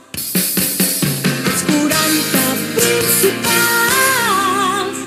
Indicatiu de la cadena en català